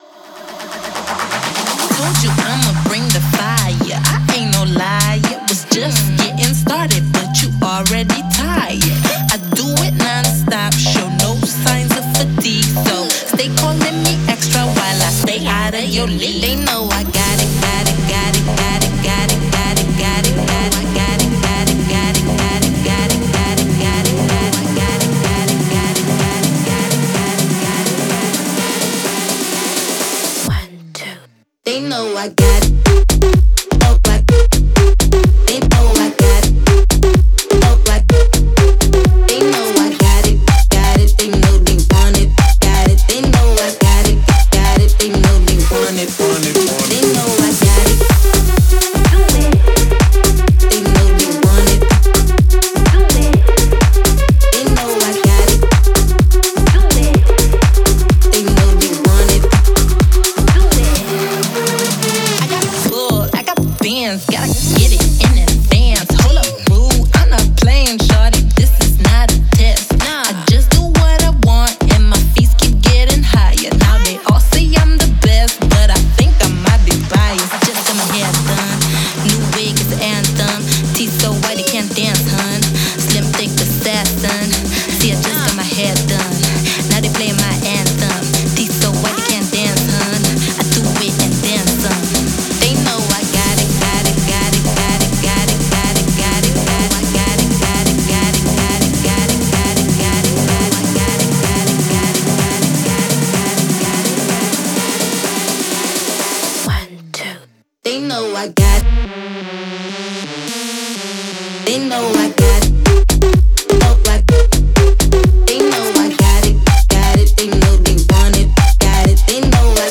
это энергичная трек в жанре хип-хоп с элементами R&B